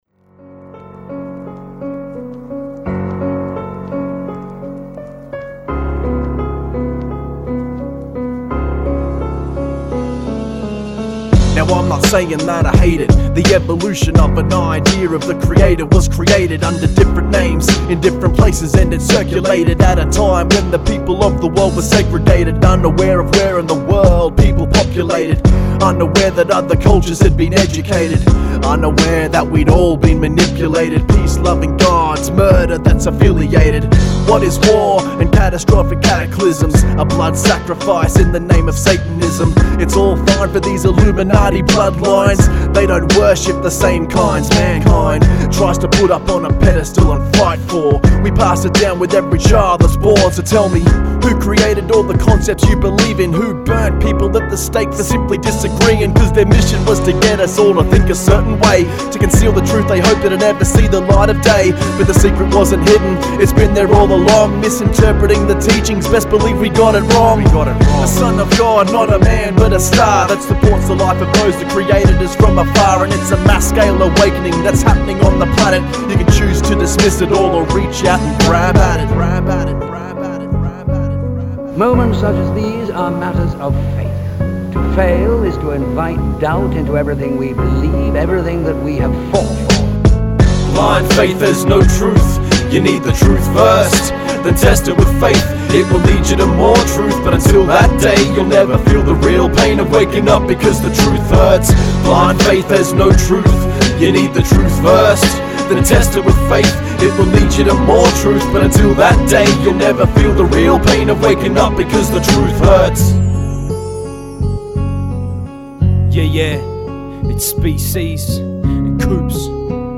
Australian hip hop